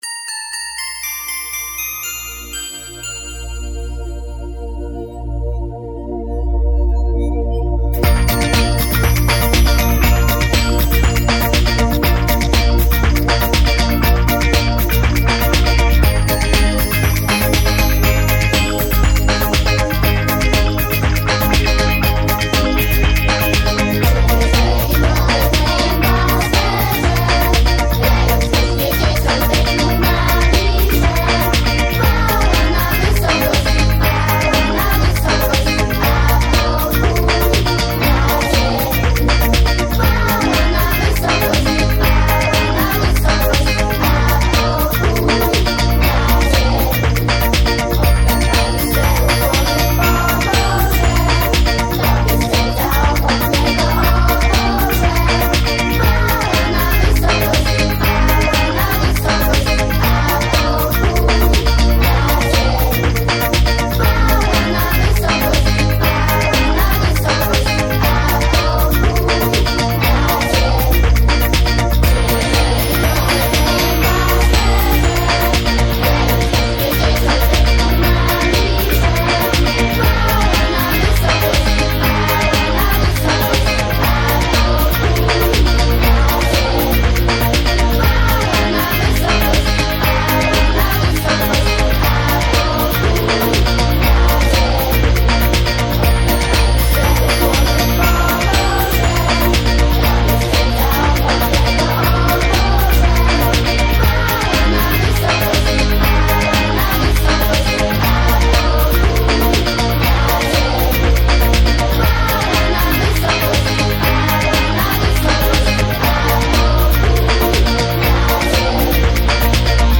Dzieciaki miały okazję uczestniczyć w profesjonalnie prowadzonym przedsięwzięciu, łącznie z wizytą w studiu nagrań Polskiego Radia Wrocław.
Śpiewające dzieci z SP2